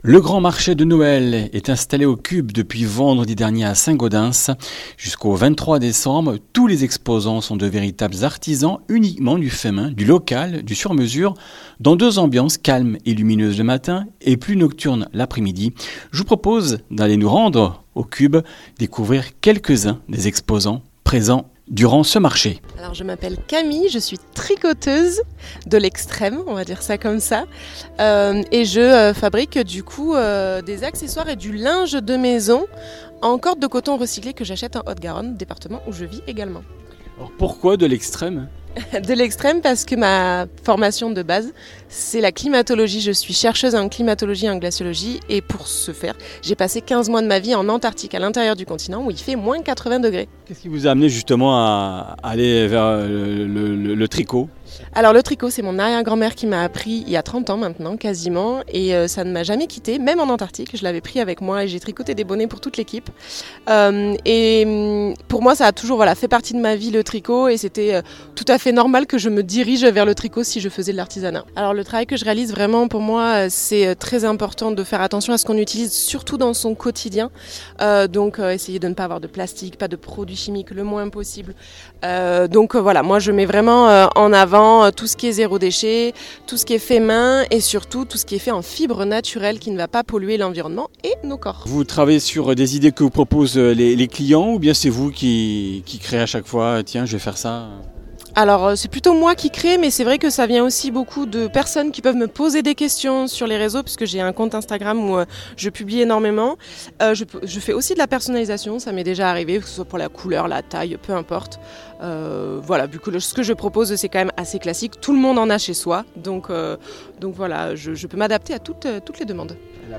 Comminges Interviews du 16 déc.